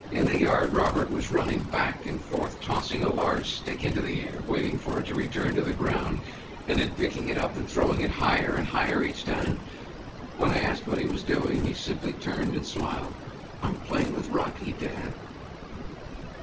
Table 2: Several examples of speech projected onto subsets of cepstral coefficients, with varying levels of noise added in the orthogonal dimensions.